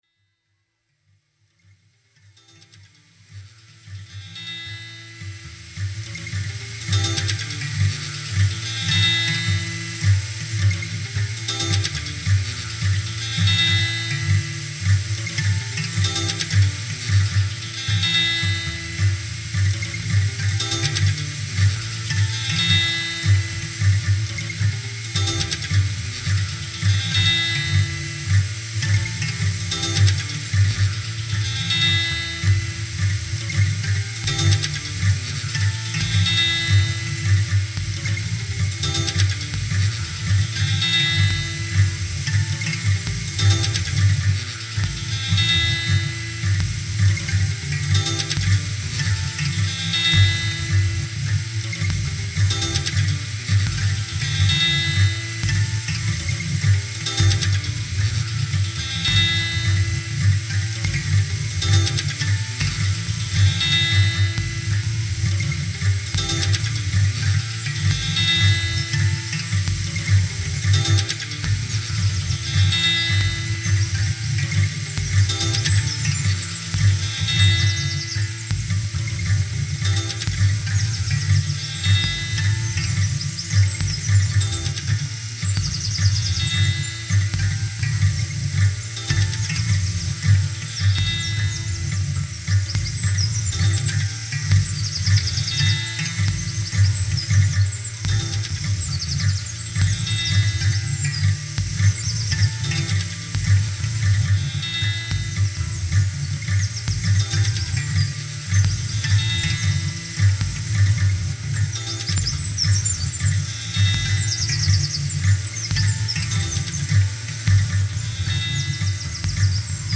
Pure, almost brute melancholic beauty.